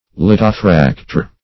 Search Result for " lithofracteur" : The Collaborative International Dictionary of English v.0.48: Lithofracteur \Lith`o*frac"teur\, n. [F., fr. li`qos stone + L. frangere, fractum, to break.] An explosive compound of nitroglycerin.
lithofracteur.mp3